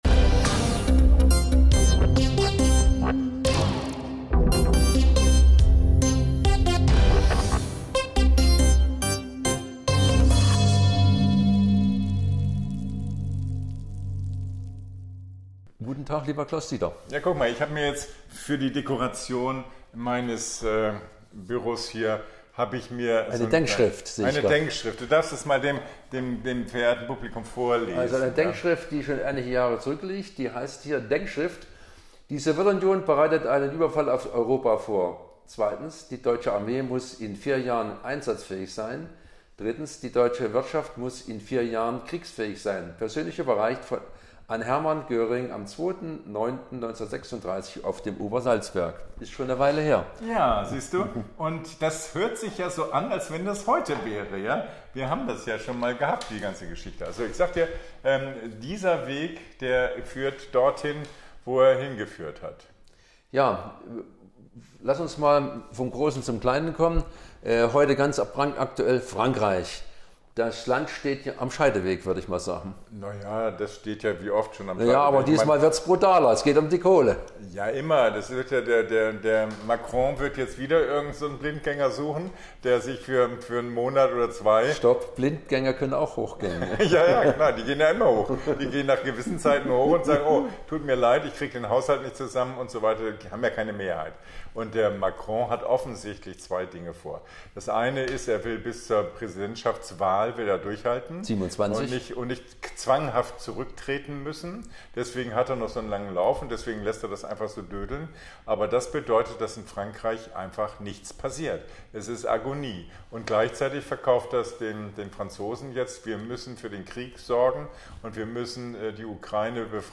Die Regierungskoalition im Freistaat muss als Ganzes liefern. Aber auch die SPD als kleinster Koalitionspartner m�sse deutlich machen, wof�r sie steht. Das erkl�rt der SPD-Landesvorsitzende und Th�ringer Innenminister Georg Maier im Sommerinterview der Th�ringer Lokalsender.